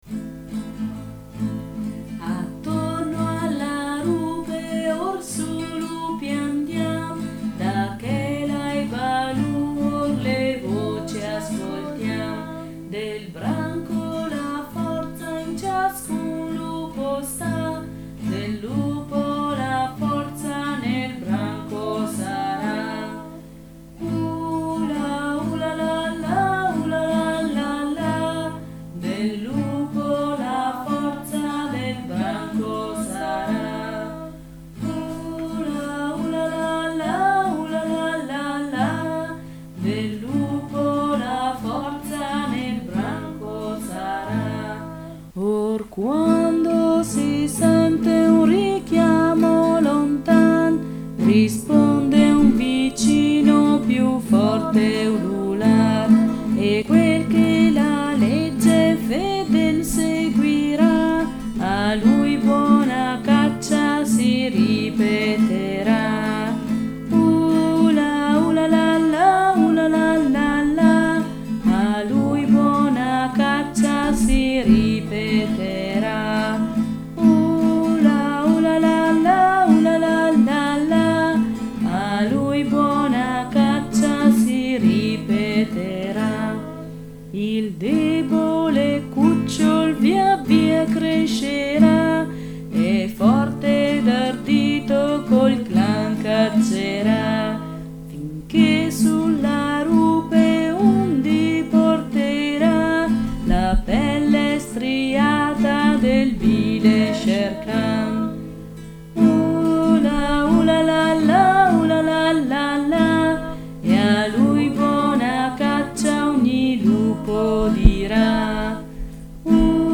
Canto da cantare all' apertura e alla chiusura del consiglio della rupe Sim Attorno alla rupe ors� lupi andiam, Mim Sim d'Akela e Baloo or le voci ascoltiam.